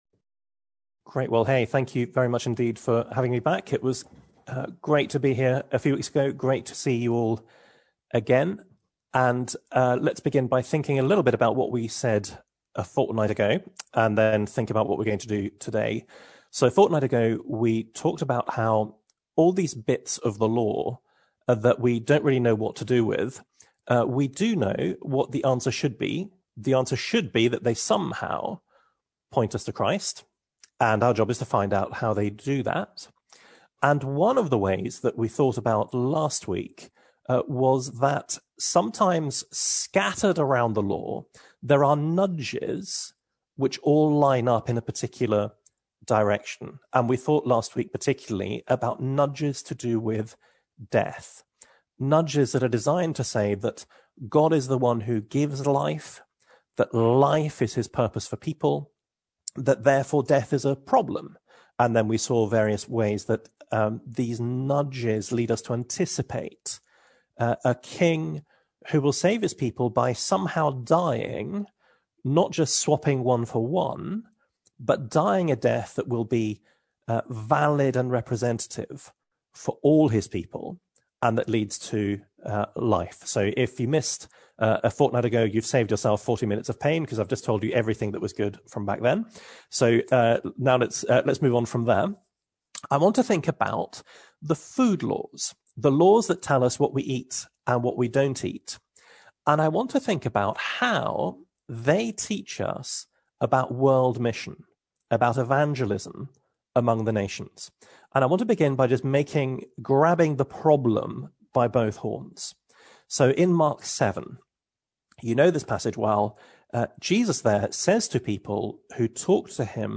Passage: Leviticus 11 Service Type: Midweek Reading and Sermon Audio